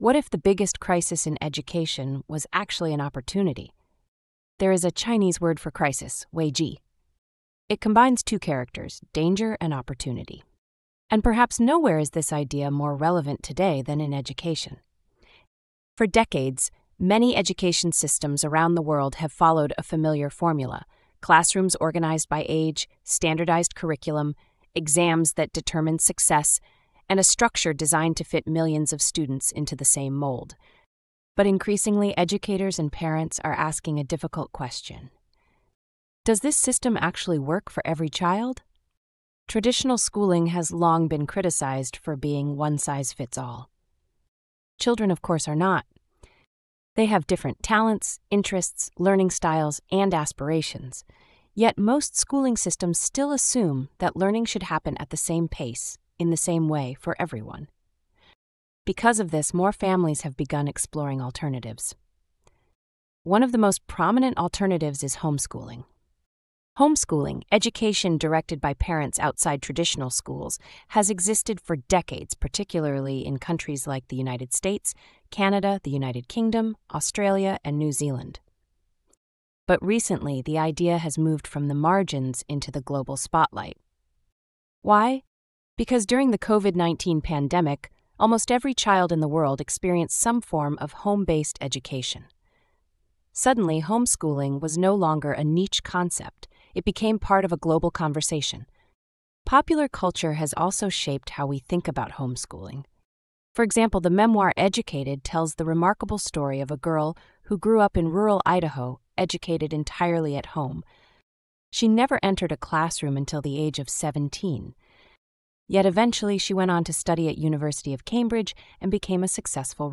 Credits: the work is based on a UNESCO GEM education report authored by SEE researcher; the Podcast content is made with AI support (Descript and ChatGPT free version).